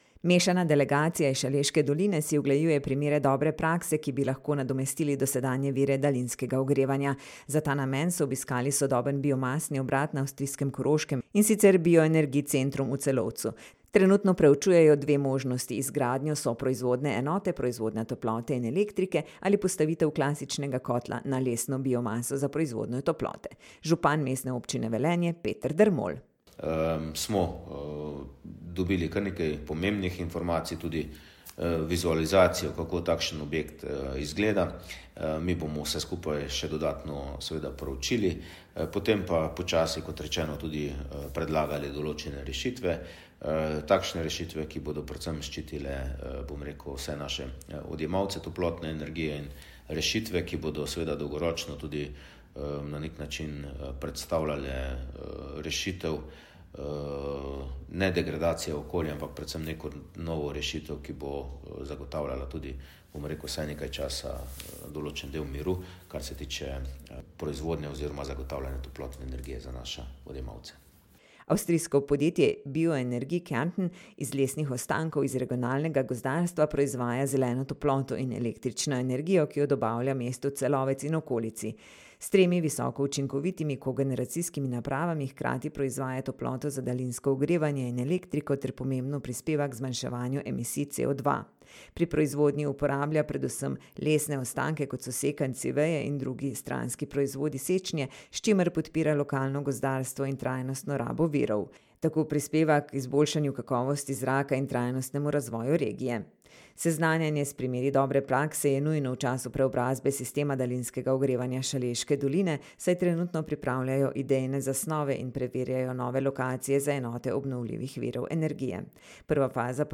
Župan Mestne občine Velenje Peter Dermol